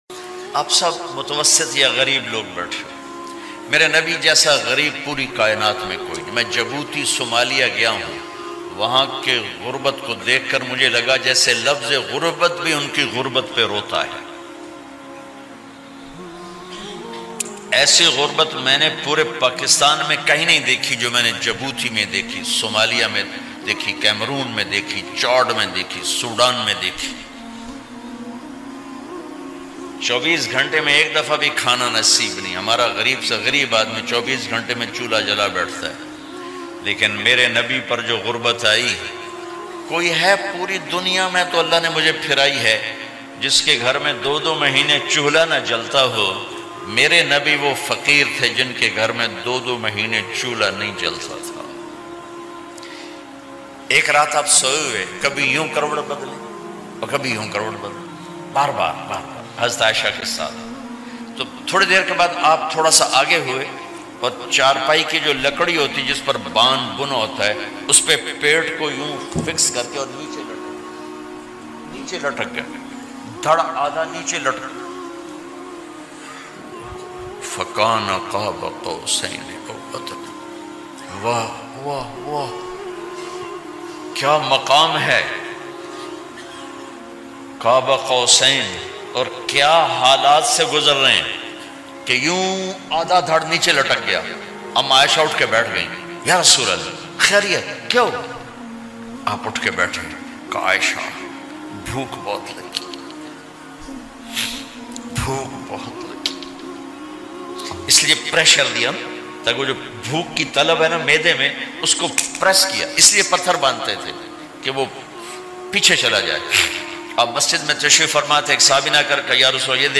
Hamaray Nabi Kay Sabar Aur Bardashat Ka Rula Dany Wala Bayan By Maulana Tariq Jameel.
Hamaray Nabi Kay Sabar Aur Bardashat Ka Bayan.mp3